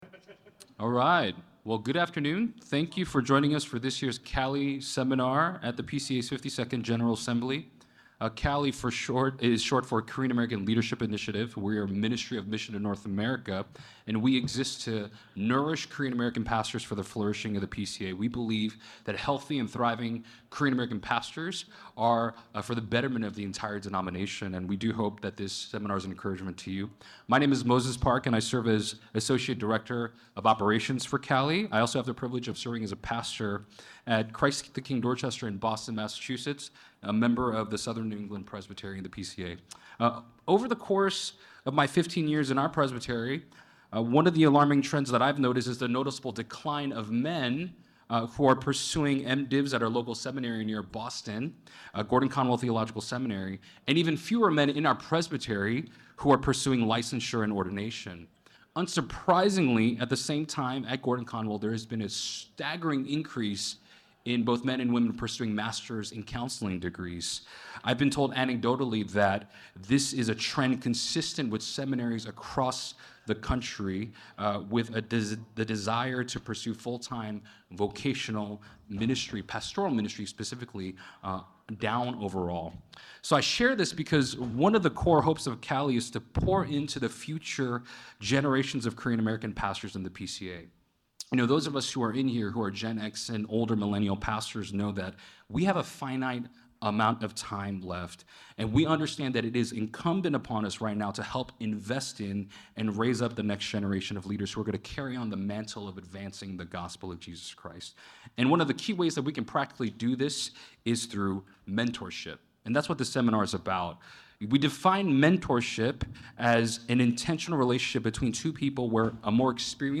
The Shifting Face of Korean-American Mentorship KALI Panel, Korean American Leadership Initiative This seminar will compare/contrast the church mentorship experience of three Korean American pastors from three different generations (Gen X, Millennial, Gen Z), discussing the strengths and weaknesses of each mentorship experience. We’ll explore the ways that each generation desires to be mentored in order to be better equipped to fruitfully mentor the next generation of Korean American leaders (younger Millennials, Gen Z, Gen Alpha).